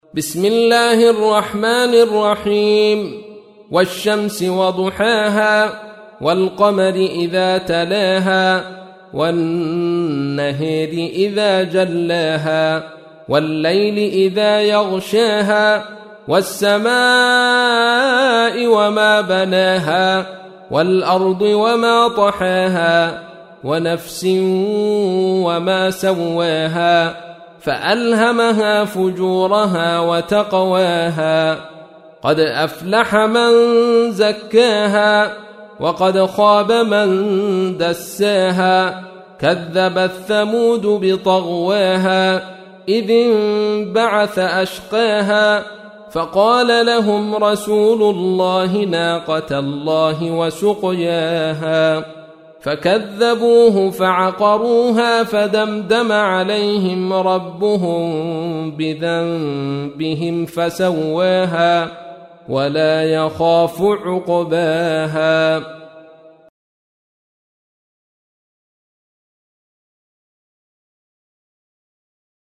تحميل : 91. سورة الشمس / القارئ عبد الرشيد صوفي / القرآن الكريم / موقع يا حسين